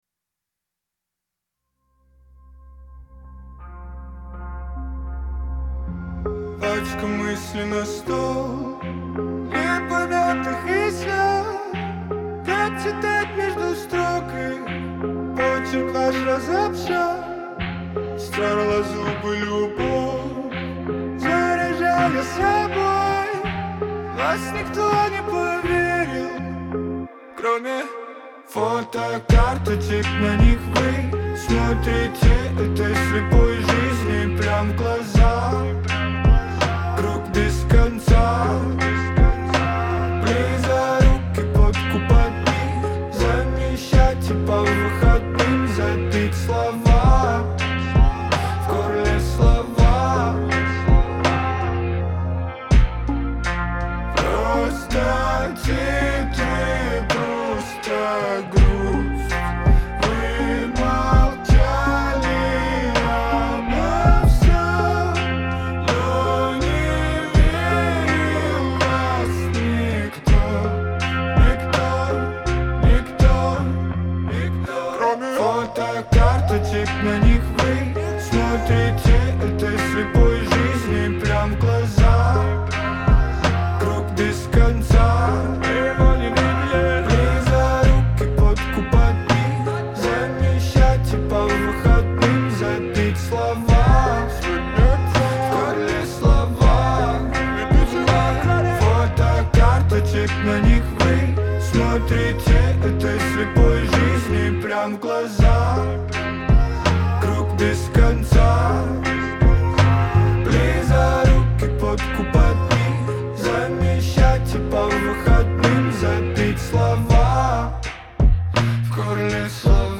Категории: Русские песни, Альтернатива.